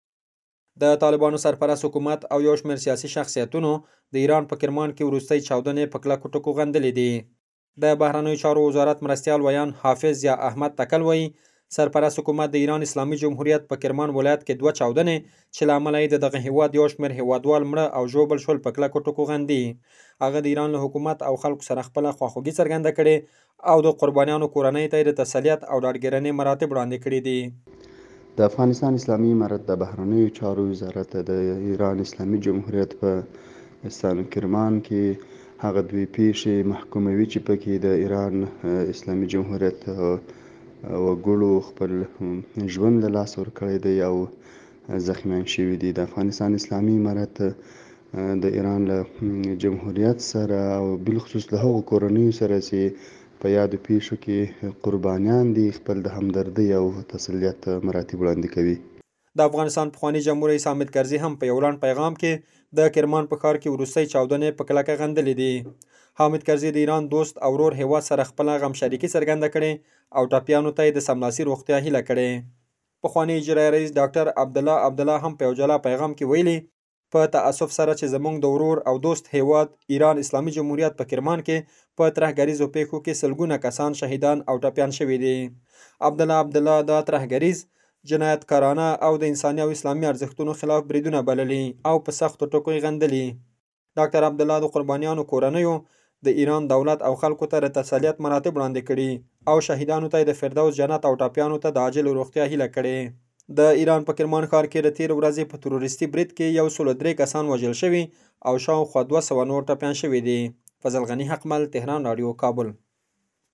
زمونږ خبریال راپور راکړی د افغانستان د طالبانو سرپرست حکومت او يو شمېر سياسي شخصيتونو د ايران په کرمان کې وروستۍ چاودنې په کلکو ټکو غندلې دي.